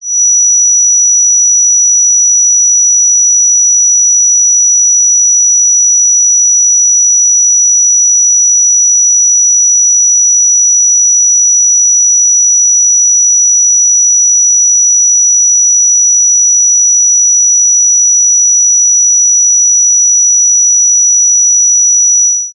Здесь можно послушать и скачать примеры гула, звона, шипения и других фантомных шумов, которые некоторые люди воспринимают субъективно.
Свист в ушах подобный ультразвуку